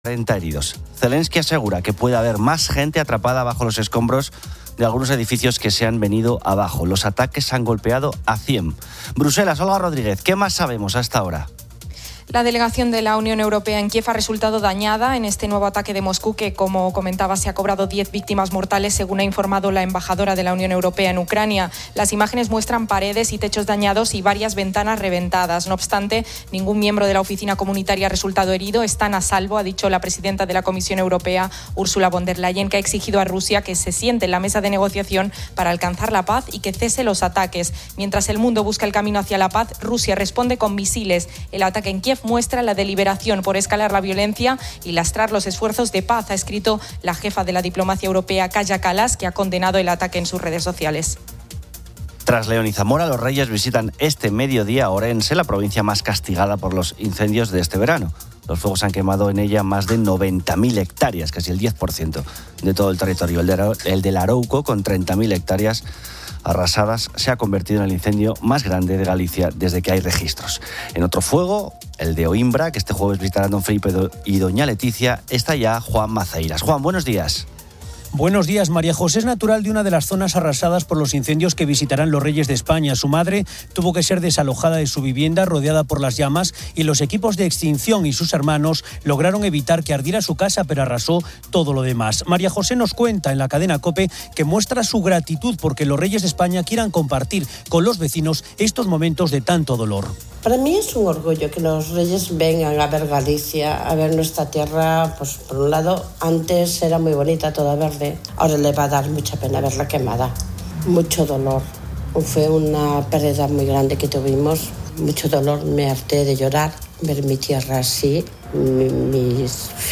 Un segmento amplio se dedica a los dibujos animados infantiles, con oyentes compartiendo sus preferencias, a cuáles están "enganchados" (como Bluey, Doraemon, Gumball, o incluso "Buba" y "Pocoyó"), y debatiendo sobre si los dibujos actuales tienen menos trama que los clásicos.